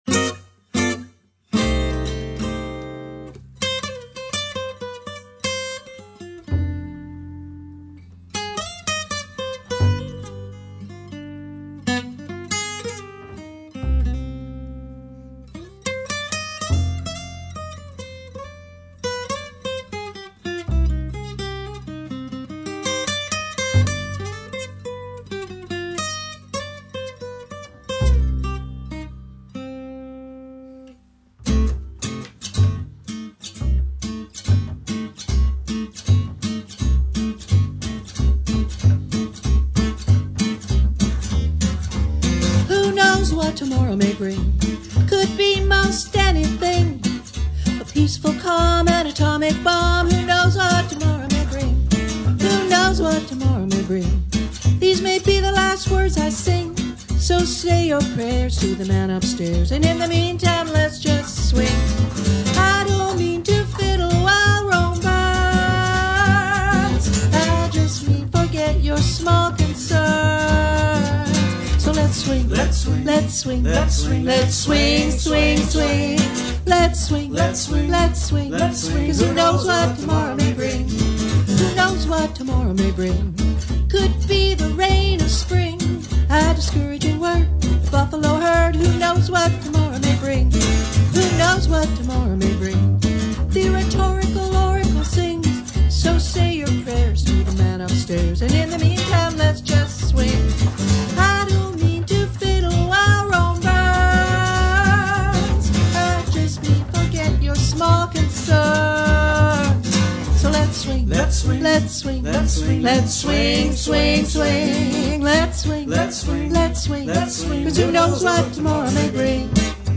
vocals and guitar
vocals, lead guitar and resophonic guitar
vocals and upright bass
Recorded live off the floor
in Deep Cove, BC.